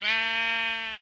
sounds / mob / sheep / say3.ogg